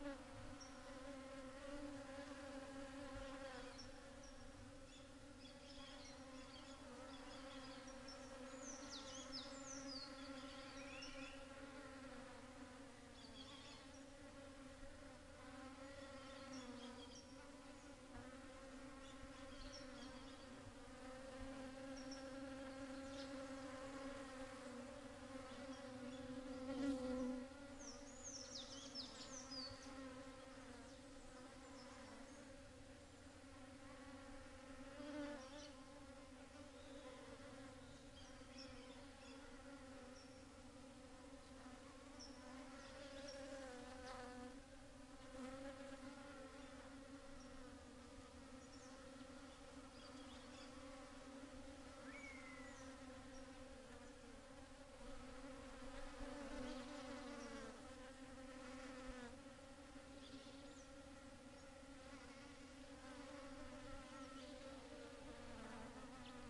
Brazil selva sounds " Mineiros Brasil selva 10.12.17 morning bees swarm 2
描述：当他们在清晨吞下盛开的百慕大草时，蜜蜂的声音嗡嗡作响。
Tag: 嗡嗡声 蜜蜂 昆虫 性质 现场录音 巴西